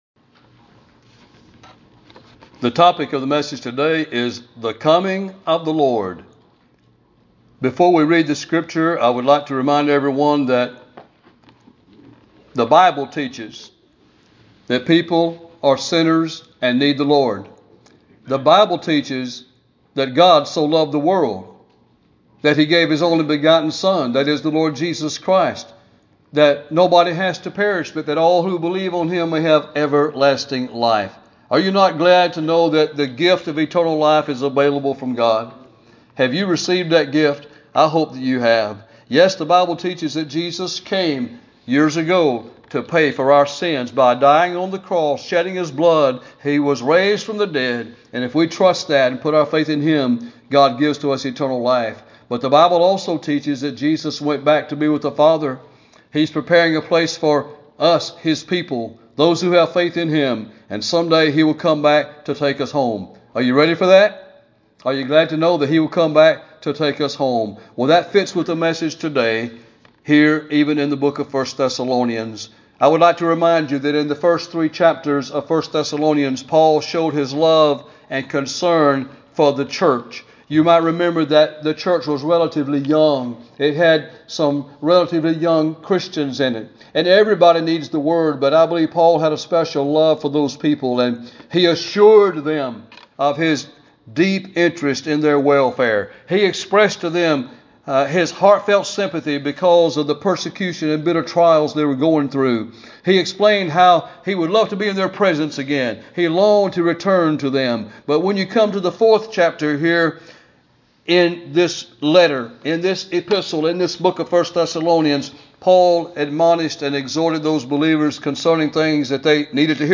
Sermons - Payne Springs Baptist Church